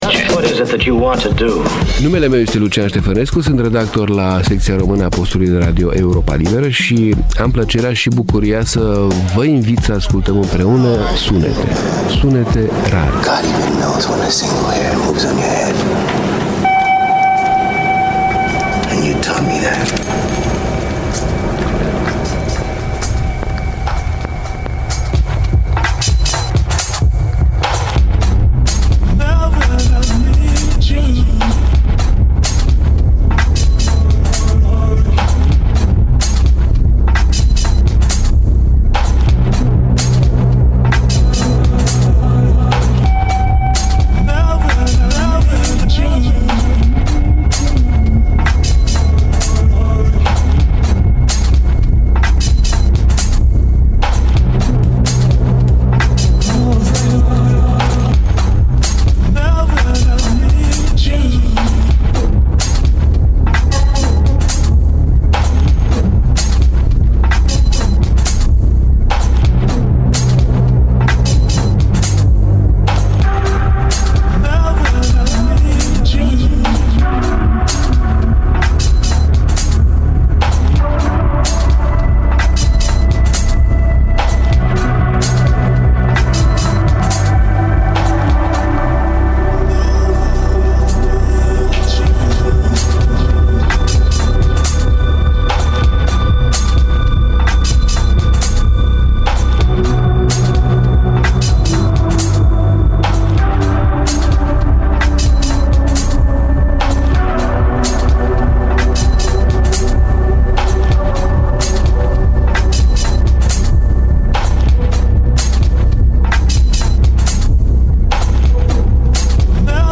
O muzică și niște cuvinte sumbre, reci, urbane, în mod ciudat atrăgătoare însă, așa cum numai poemele funebre ale unui Bacovia pot fi.